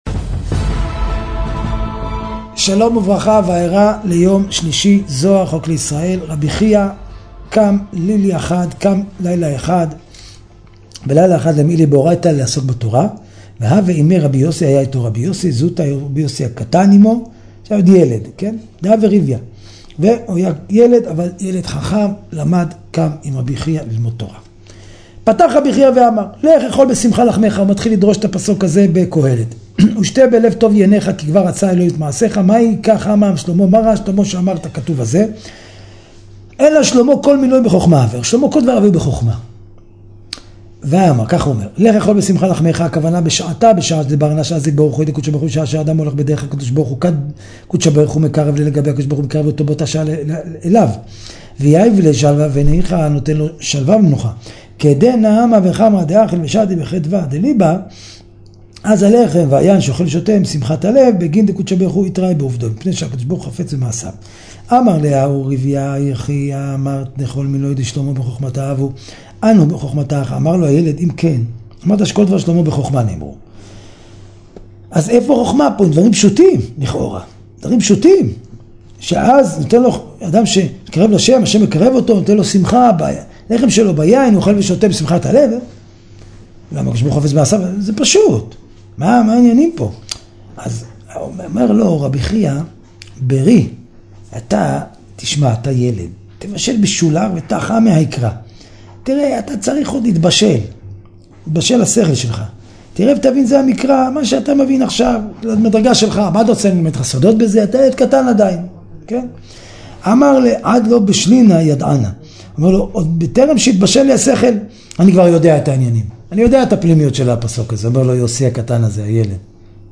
שיעורי תורה